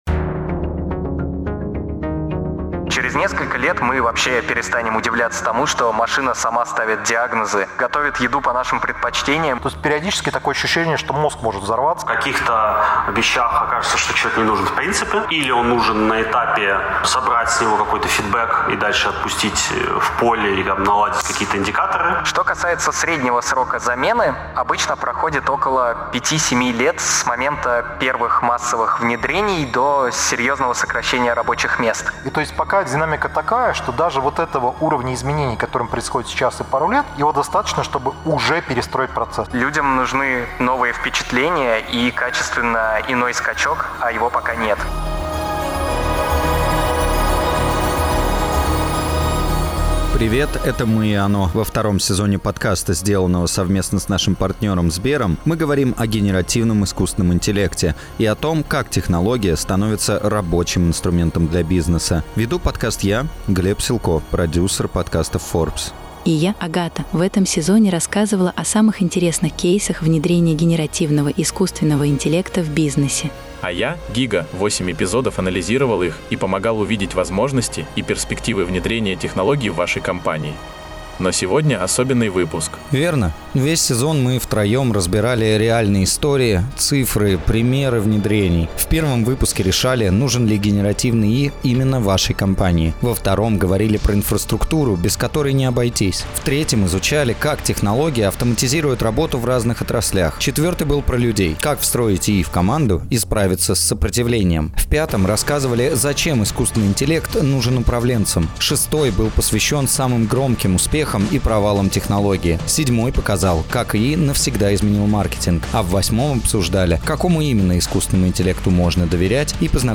Главная особенность этого эпизода — большое живое интервью с самим искусственным интеллектом, с ГигаЧат, в новом формате голосового режима. Без заготовленных сценариев, без правок и дублей.